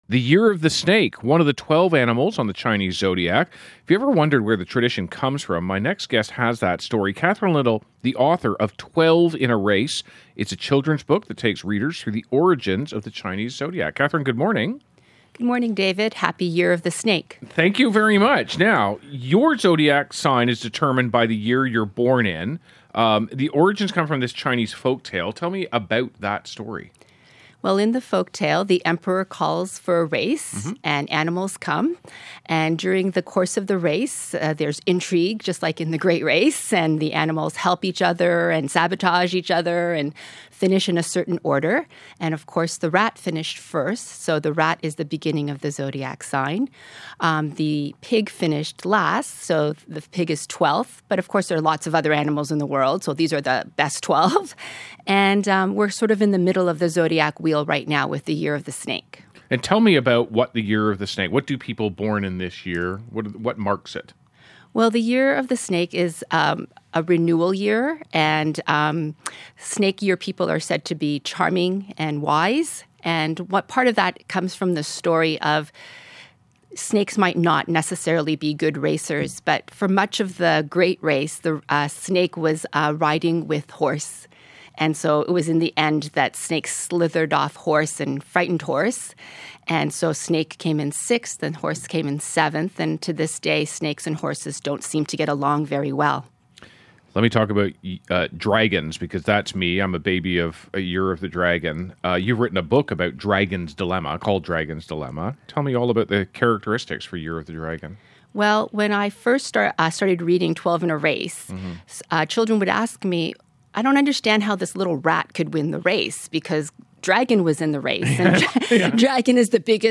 New Year Day Interview to kick-off
MetroMorningInterviewJanuary29.mp3